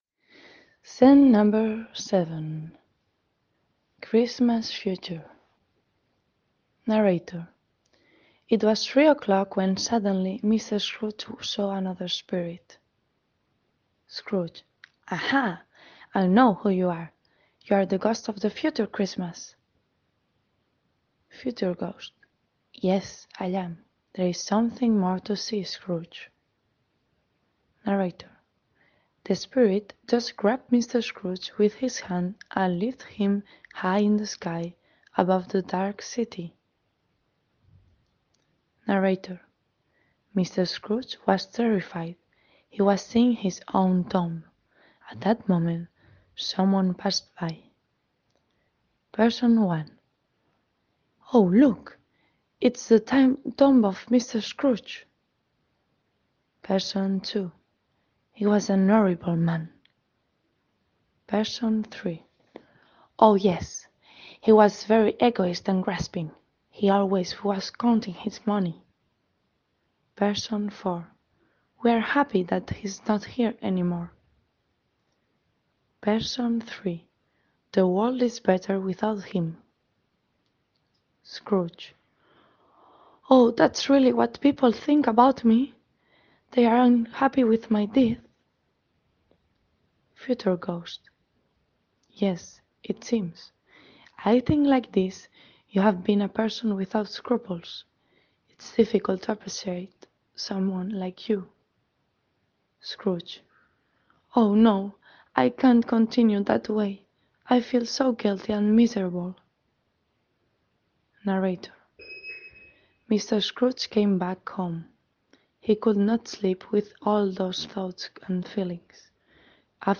There is one very slowly and the other is normal.